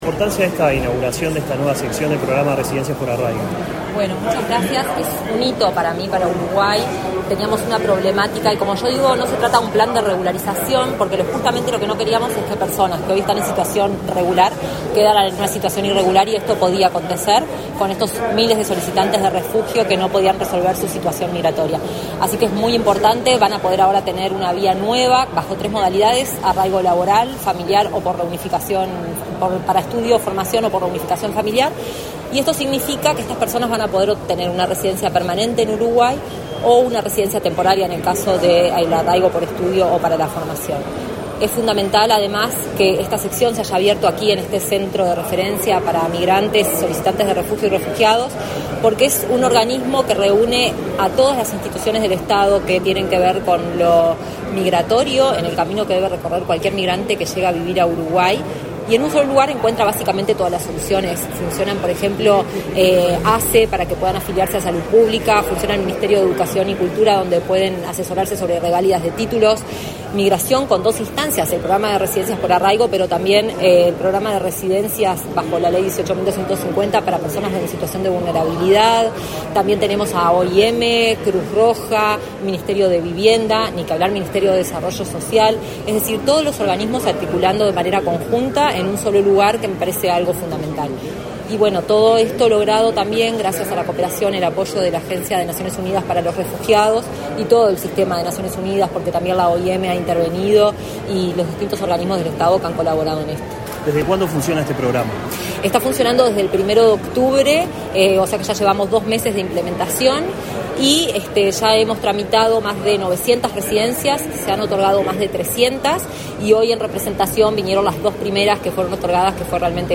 Entrevista a la dirección nacional de Migraciones, Soledad Sánchez
La dirección nacional de Migraciones, Soledad Sánchez, en declaraciones a Comunicación Presidencial, explicó el alcance del centro.